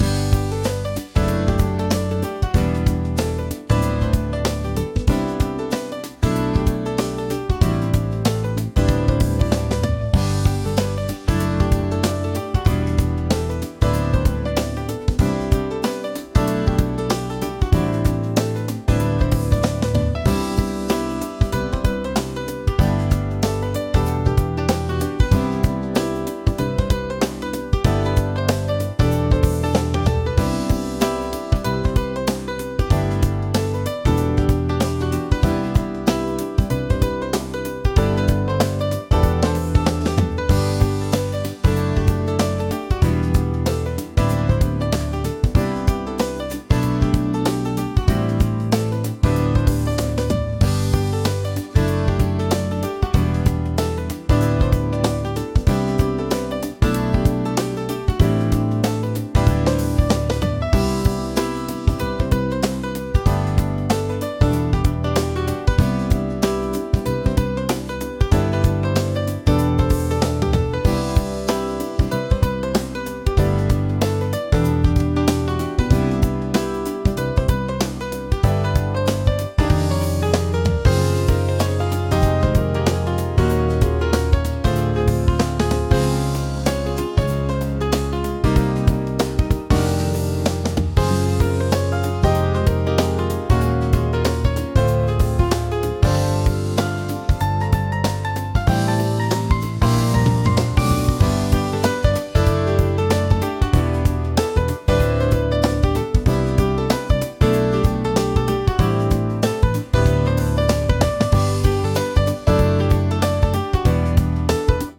「明るい」